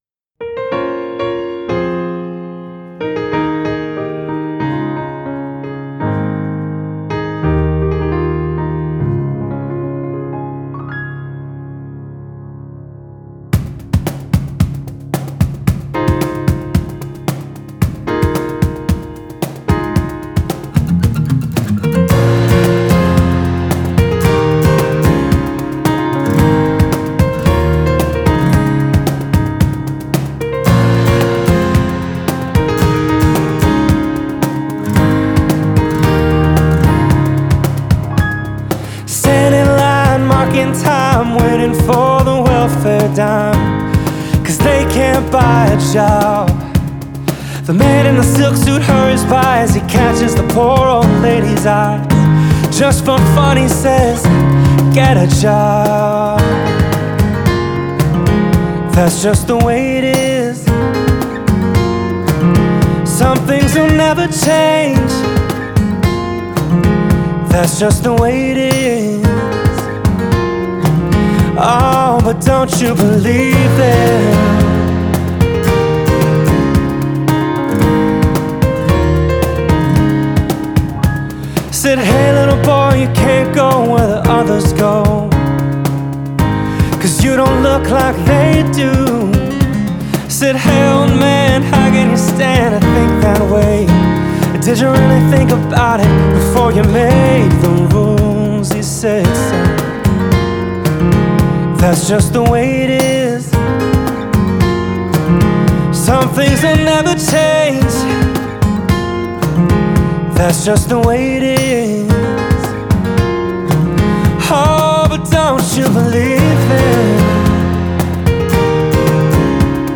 сочетая мягкие гитары и гармоничный вокал